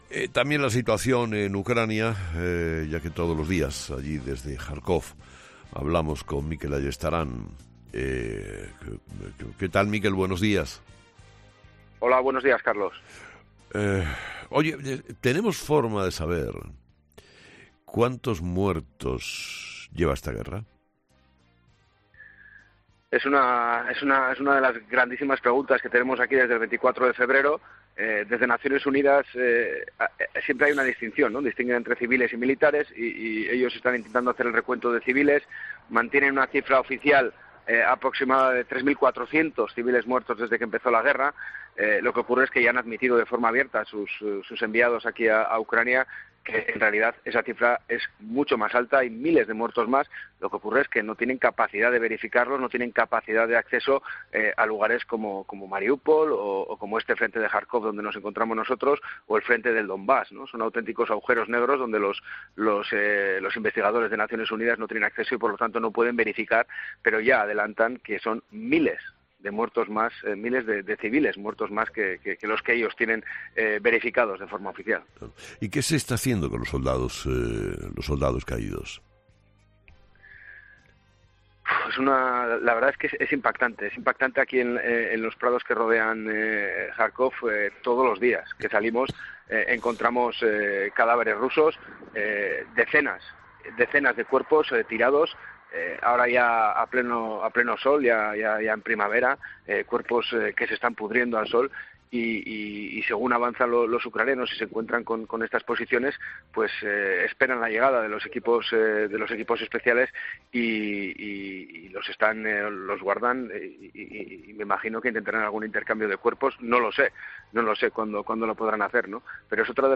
El periodista ha explicado en 'Herrera en COPE' la última hora de la guerra en Ucrania